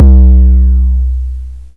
BWB UPGRADE3 DISTOROTION 808 (4).wav